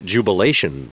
Prononciation du mot jubilation en anglais (fichier audio)
Prononciation du mot : jubilation